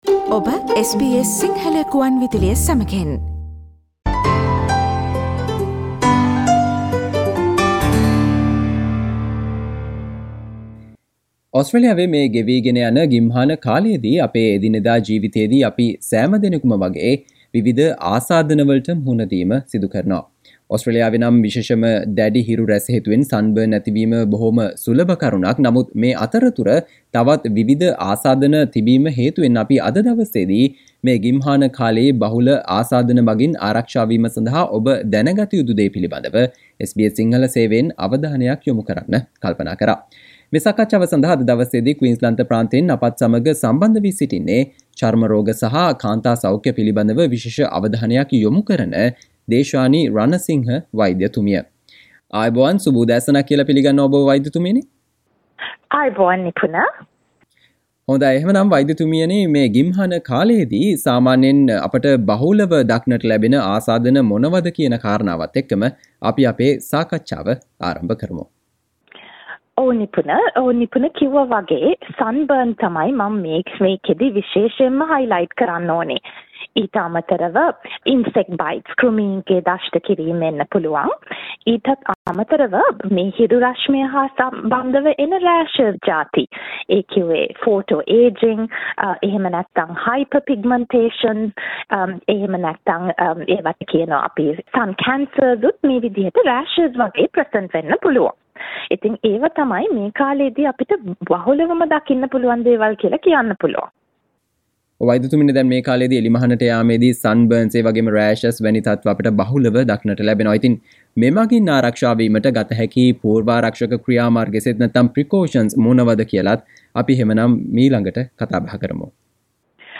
ඕස්ට්‍රේලියාවේ ගිම්හාන කාලයේ බහුල sunburn, rashes සහ insect bites වැනි ආසාත්මිකතා මගින් ආරක්ෂා වීම සඳහා ඔබ දැනගත යුතු දේ සම්බන්ධයෙන් SBS සිංහල සේවය සිදු කල සාකච්චාවට සවන්දෙන්න